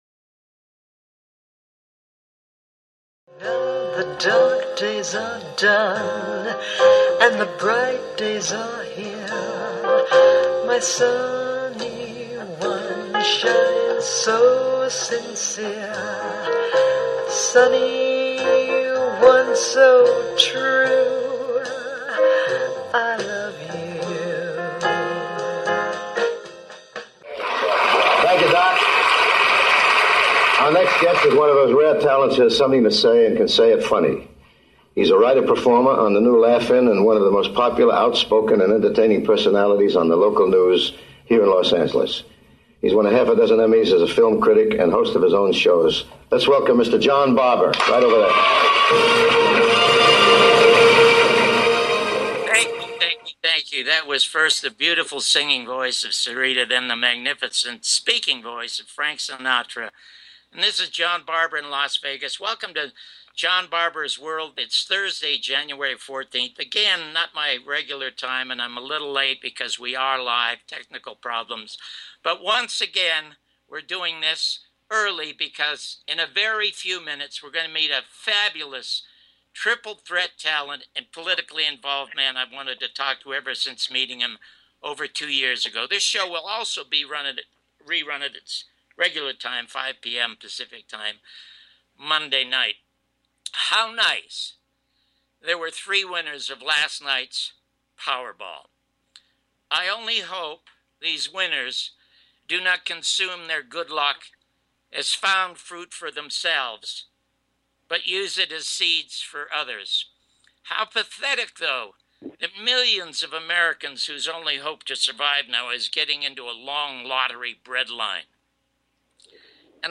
Talk Show Episode
1st Part interview with Richard Belzer